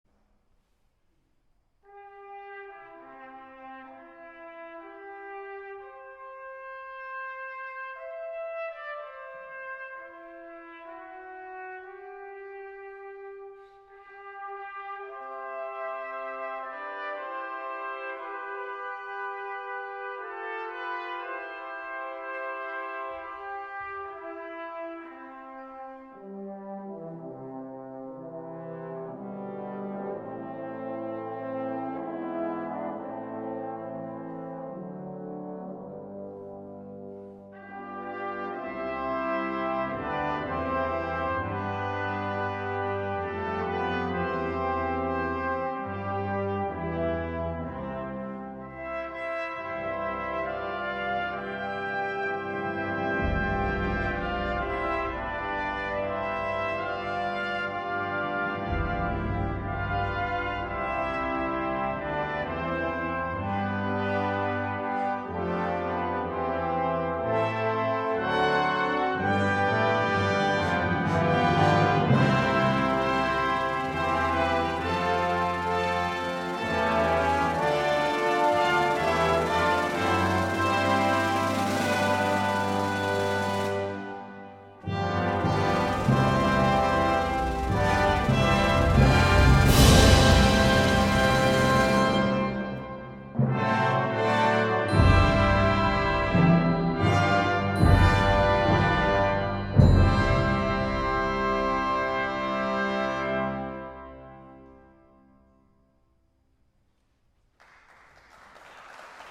adapted for brass and percussion